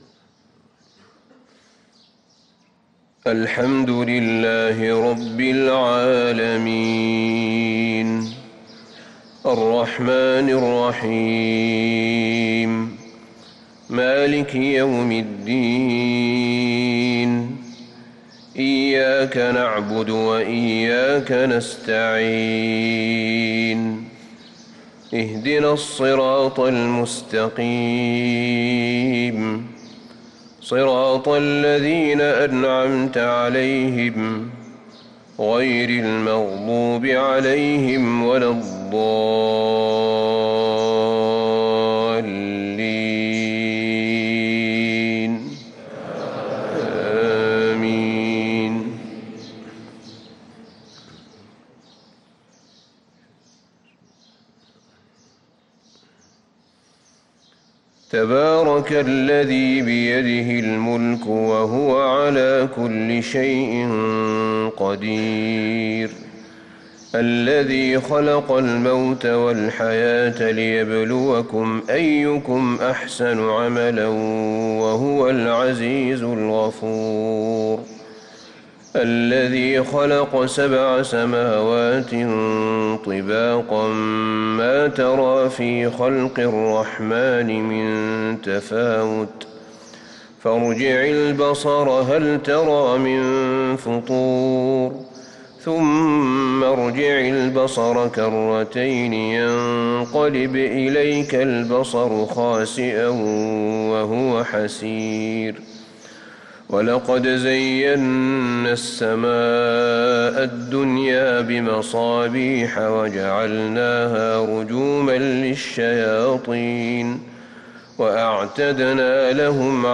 صلاة الفجر للقارئ أحمد بن طالب حميد 23 رجب 1444 هـ